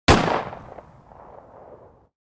realistic-gunshot-sound-effect_bnI1nz2.mp3